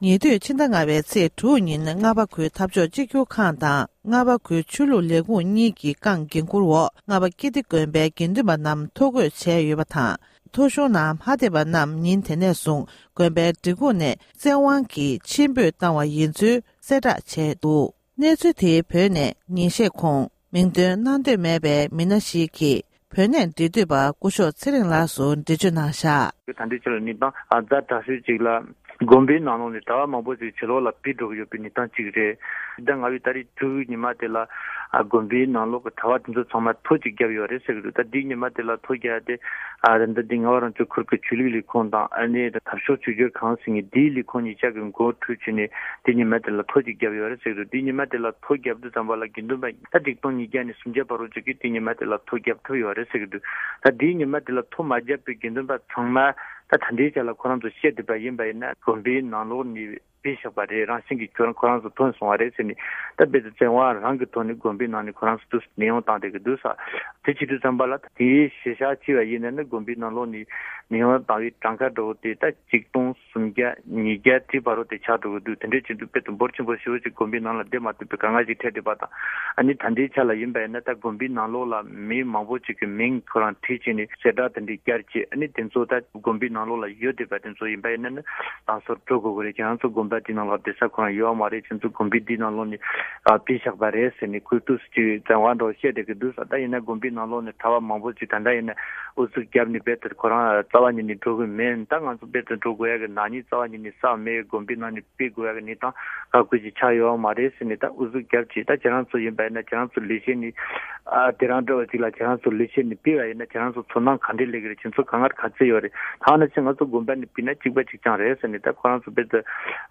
སྒྲ་ལྡན་གསར་འགྱུར། སྒྲ་ཕབ་ལེན།
གནས་འདྲི་ཞུས་ཡོད༎